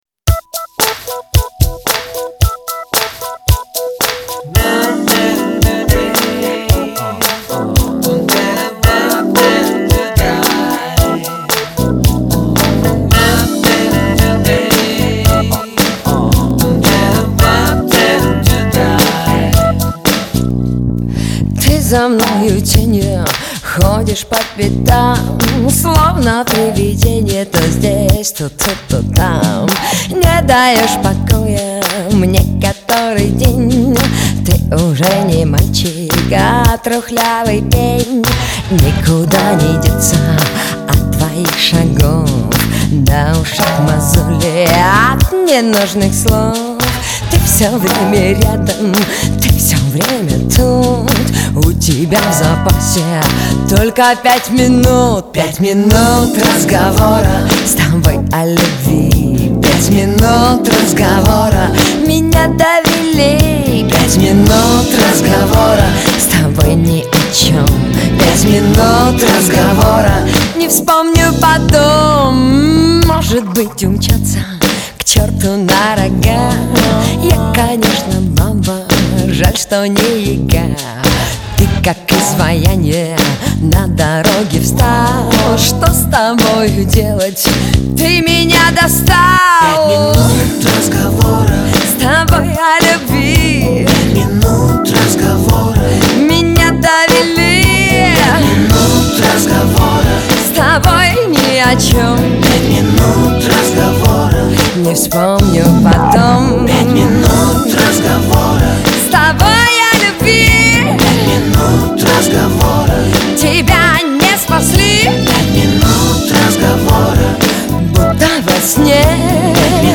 Жанр: Pop